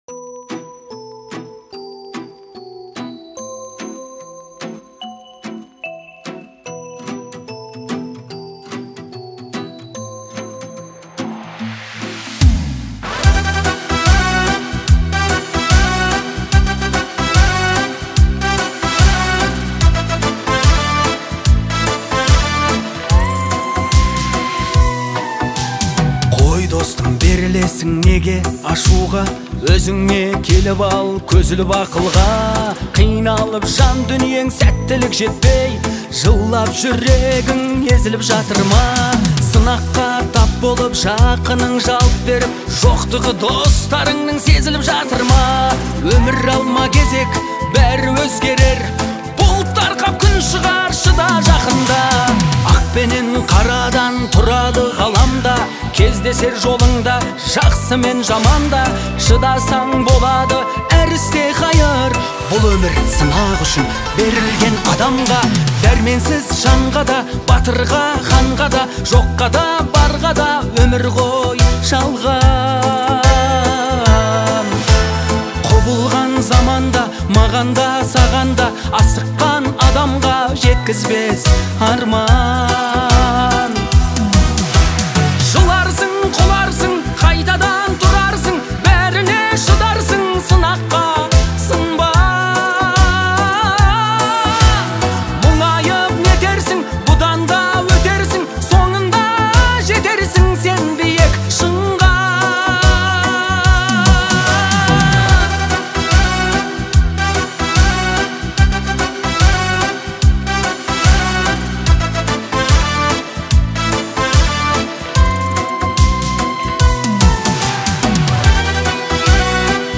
Категория: Казахские,